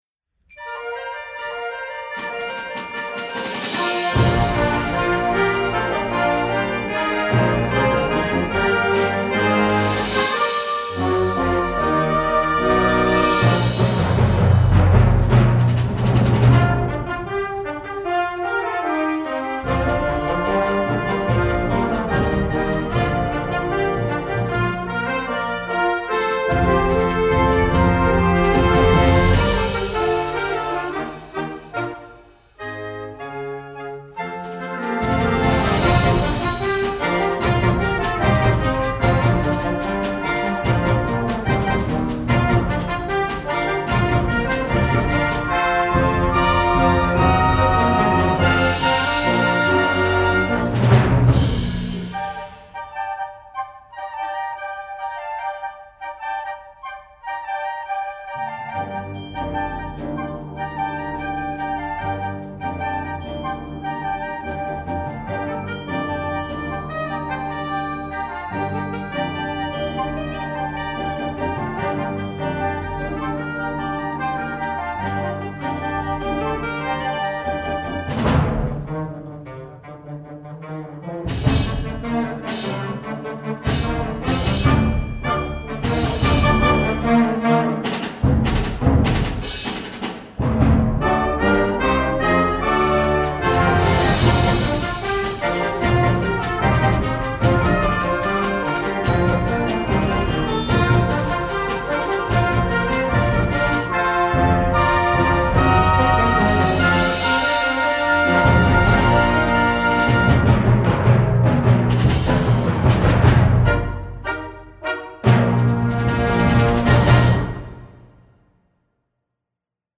Gattung: Konzertwerk für Jugendblasorchester
Besetzung: Blasorchester